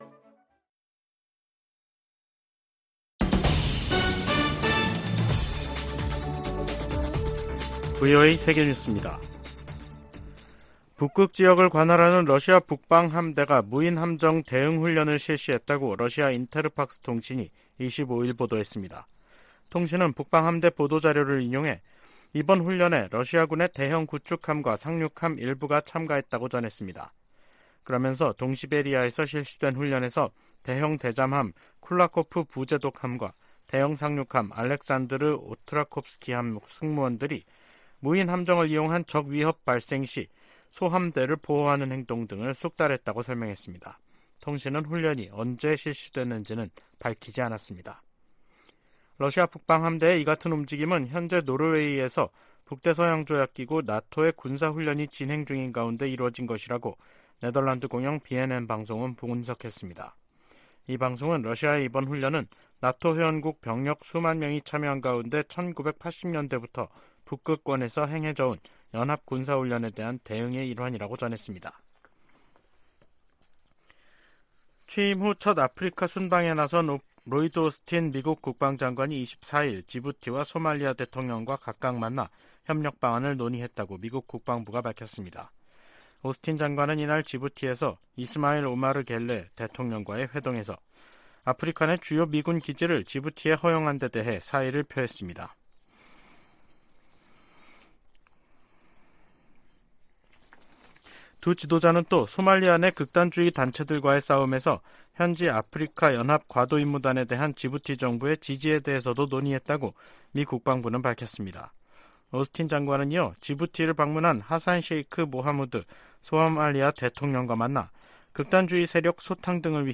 VOA 한국어 간판 뉴스 프로그램 '뉴스 투데이', 2023년 9월 25일 3부 방송입니다. 조 바이든 미국 대통령은 러시아가 우크라이나의 평화를 가로막고 있다면서 이란과 북한으로부터 더 많은 무기를 얻으려 하고 있다고 비판했습니다. 시진핑 중국 국가주석이 한국 방문 의사를 밝히고 관계 개선 의지를 보였습니다. 미국, 일본, 인도, 호주 4개국이 유엔 회원국에 북한과 무기 거래를 하지 말 것을 촉구했습니다.